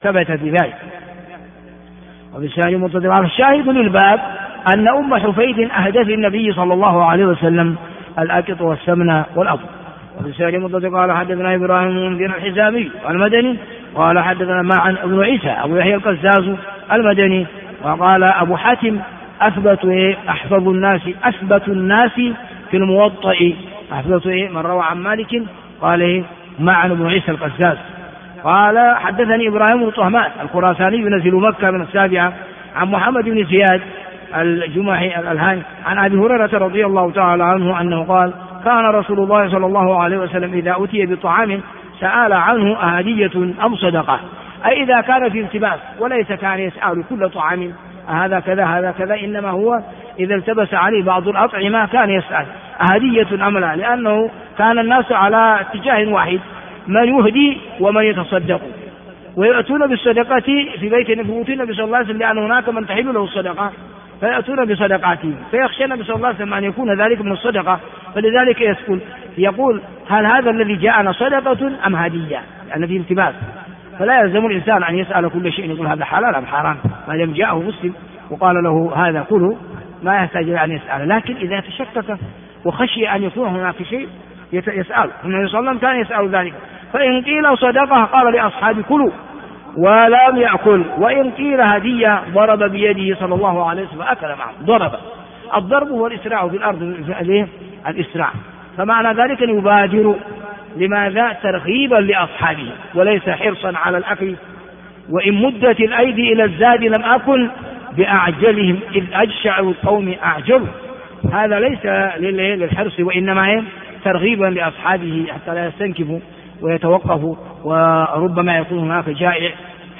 الدرس 175